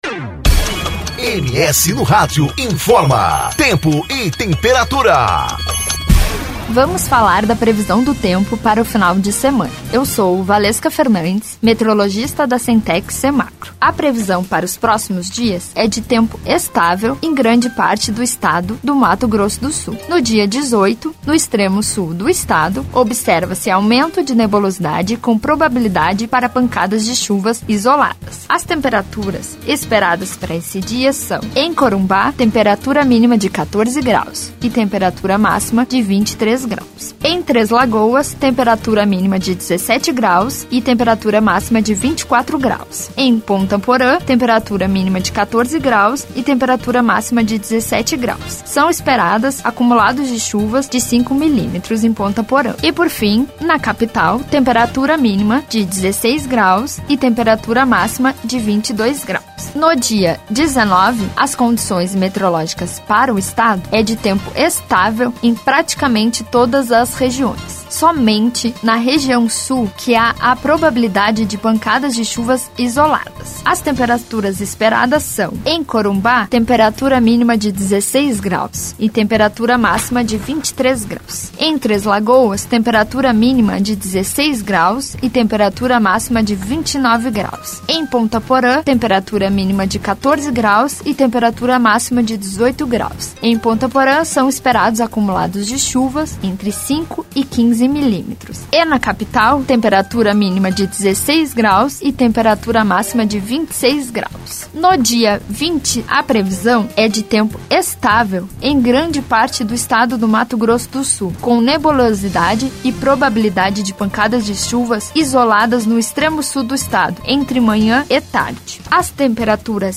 Previsão do Tempo: Fim de semana de tempo firme e temperaturas amenas